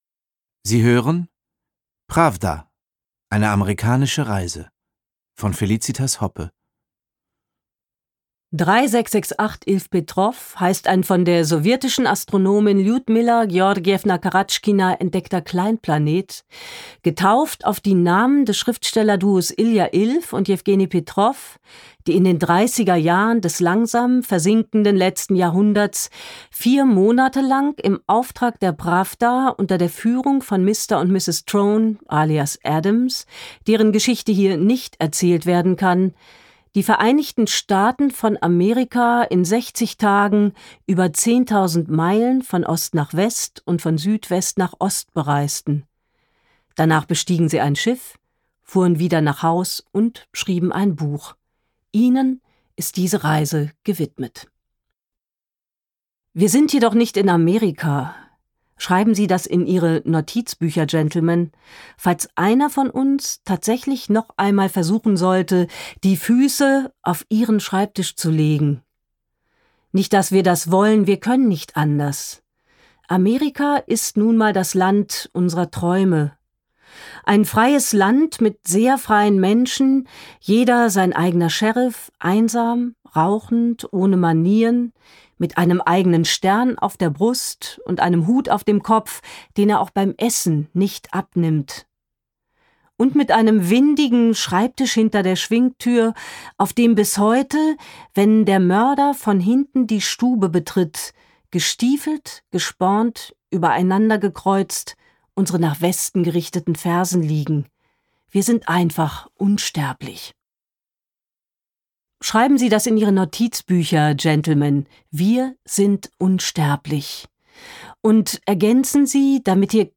Felicitas Hoppe (Sprecher)